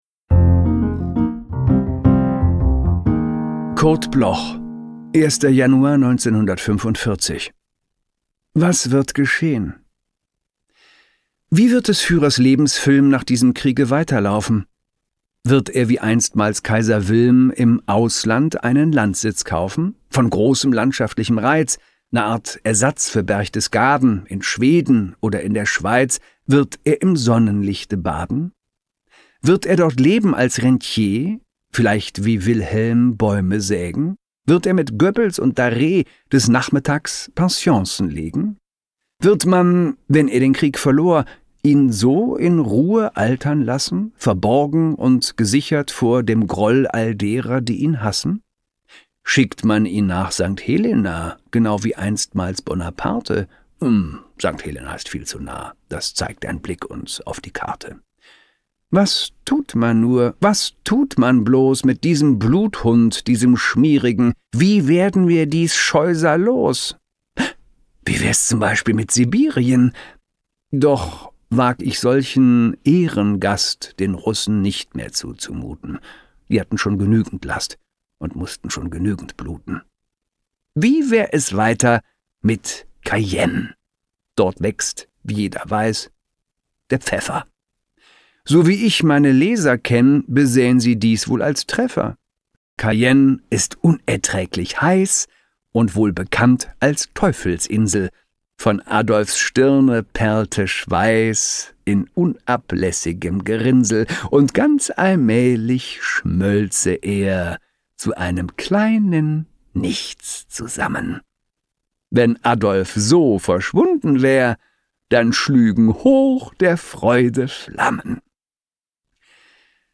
Christoph Maria Herbst (* 1966) ist ein deutscher Schauspieler und Sprecher.